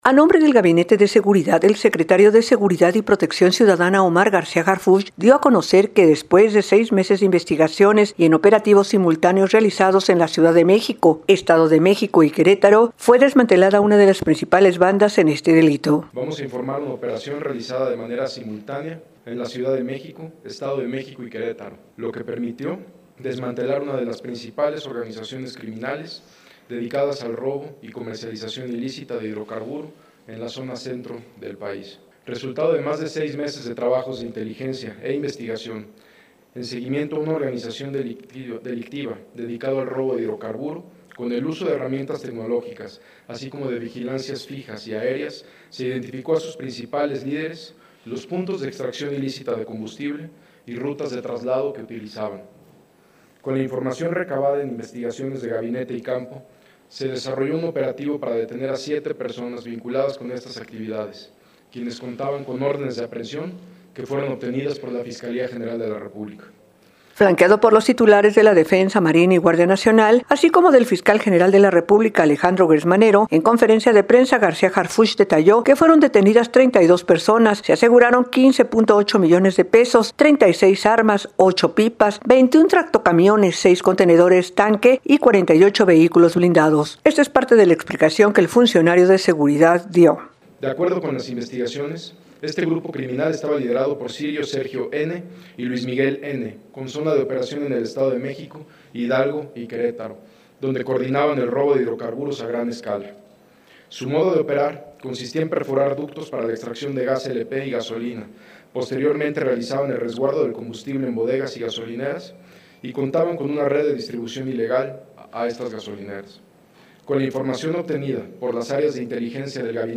Flanqueado por los titulares de Defensa, Marina y Guardia Nacional, así como por el Fiscal General de la República, Alejandro Gertz Manero, en conferencia de prensa, García Harfuch detallo que fueron detenidas 32 personas, se aseguraron 15.8 millones de pesos, 36 armas, ocho pipas, 21 tractocamiones, seis contenedores tanque y 48 vehículos blindados. Parte de lo que explico el funcionario de seguridad.